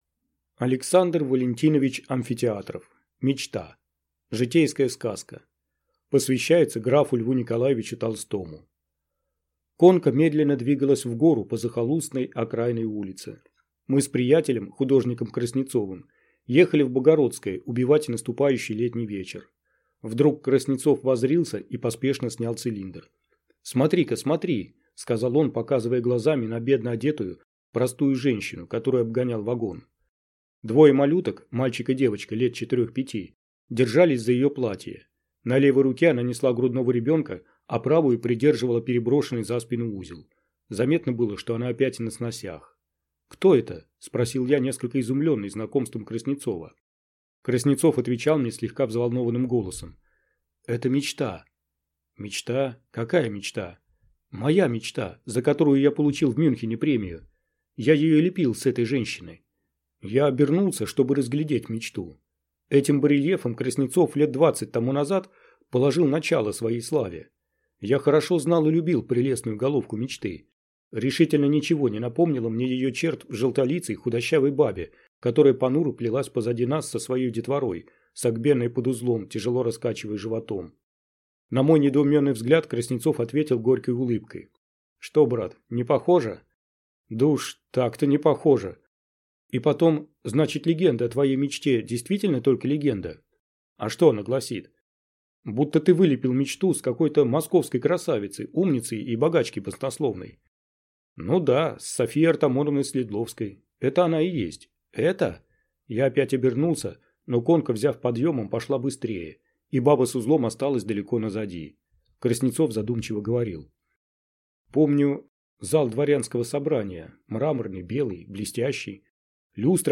Аудиокнига Мечта | Библиотека аудиокниг